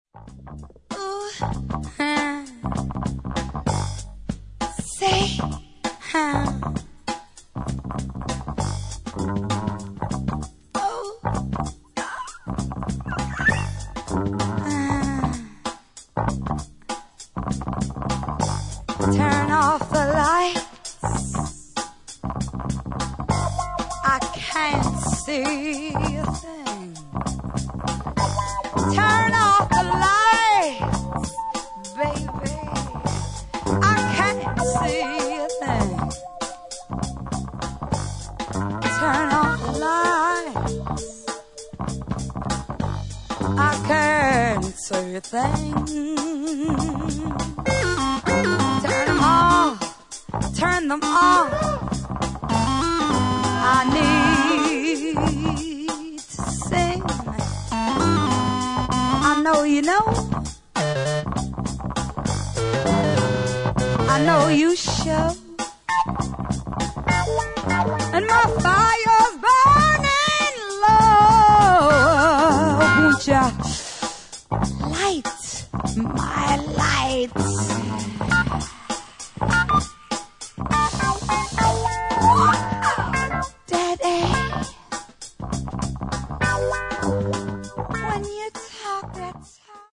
レイドバックしたグルーヴにスペーシーなMOOGやソウル・ヴォーカルで展開するコズミック・ファンク